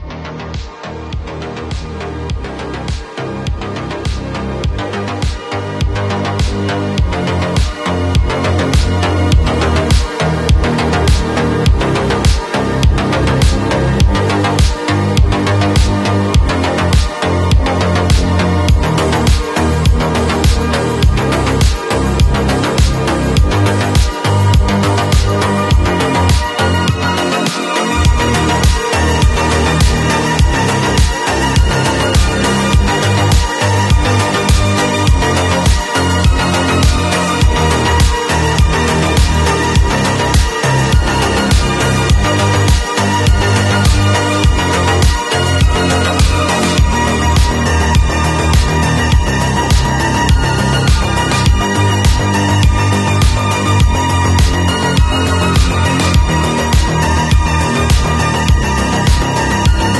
Cabview Class 801 Z Benton Sound Effects Free Download